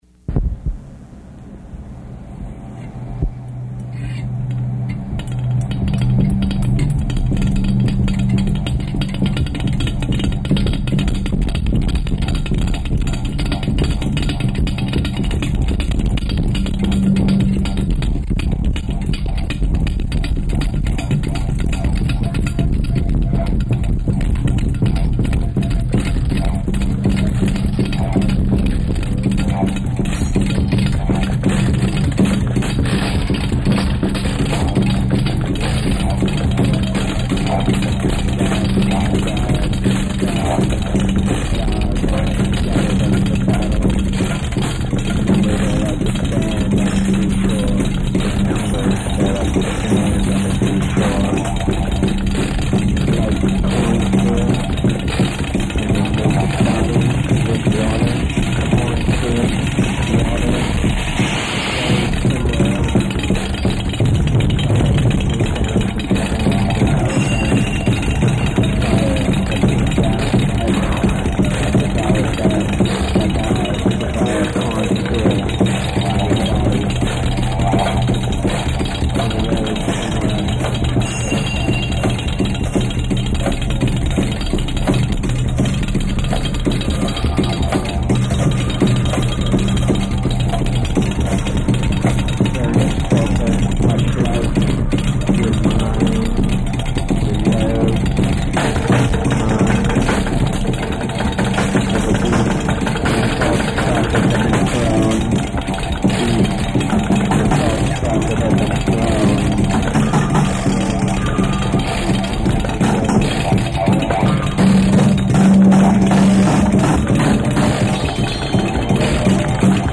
recorded by the band at Studios 509